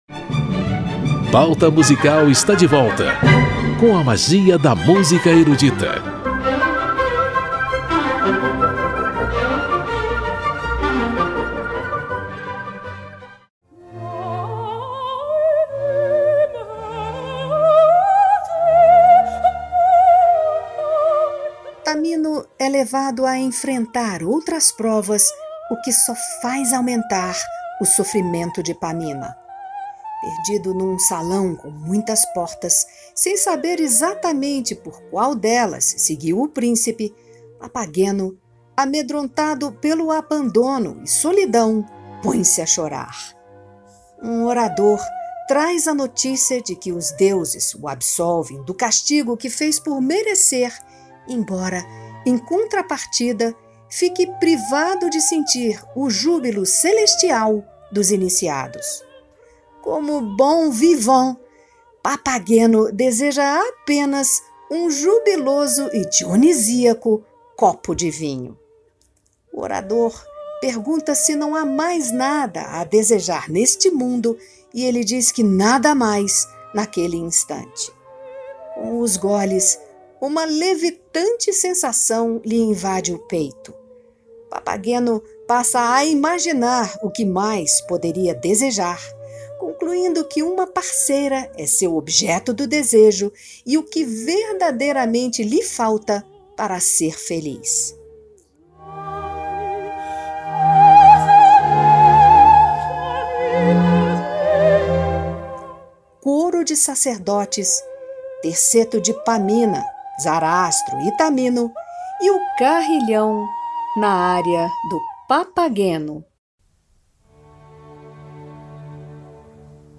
a ópera
na voz de renomados solistas